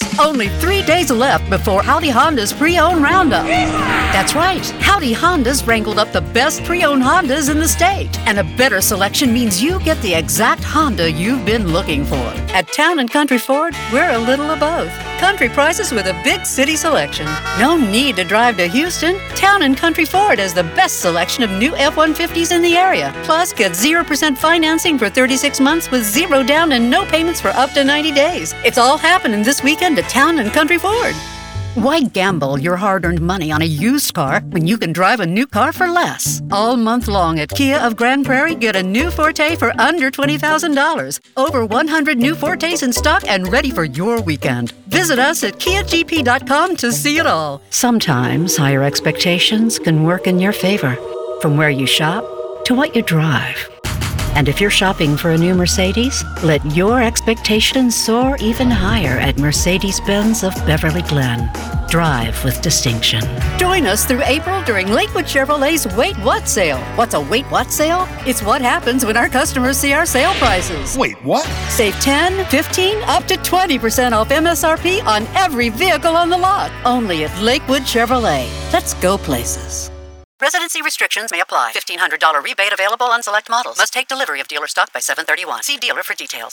Her enchanting alto voice is very listenable.
Your Next Automotive Dealership Voice - Demo Reel
My alto voice is naturally resonant and warm, with broad pitch and vocal age ranges.